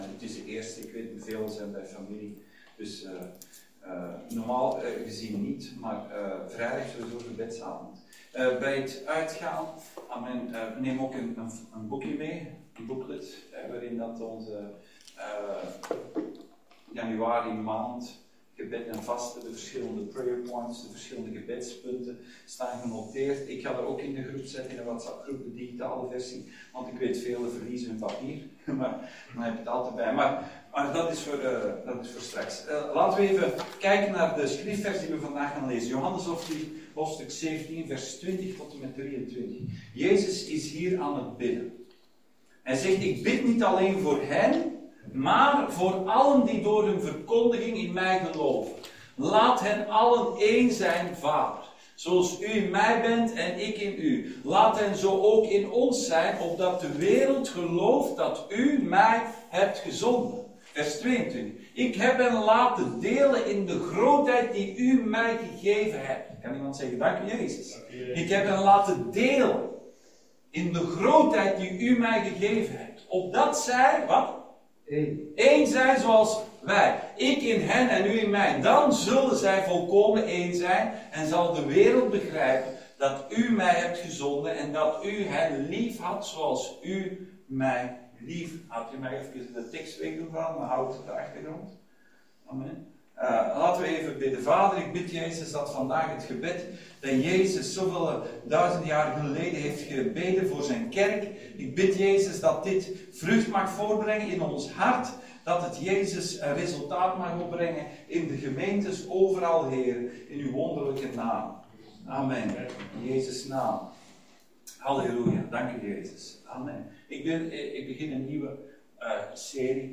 Krachtige Gebeden Dienstsoort: Zondag Dienst « Het Grootste Geschenk